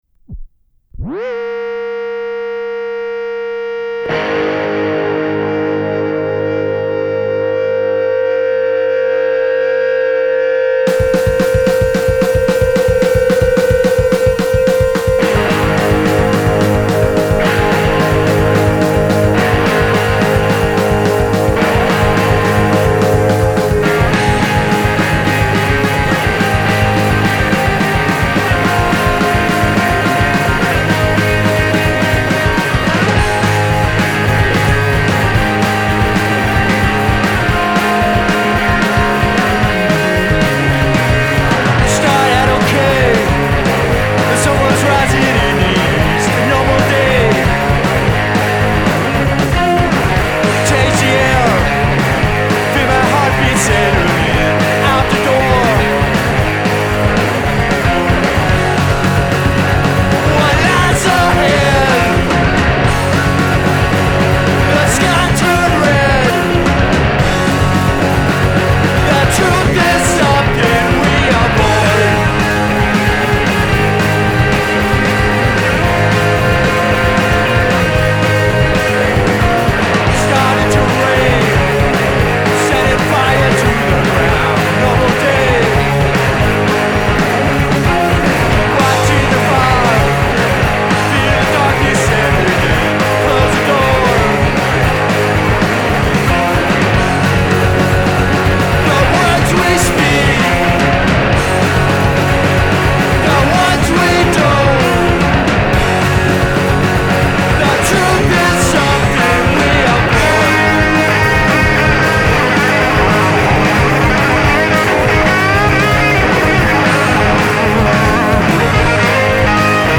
surf-inspired punk
Lead Vocals and Guitar
Drums and Percussion
Fender Bass, Radio Voice
Fuzz Guitar and Vocals
Basic live tracks engineered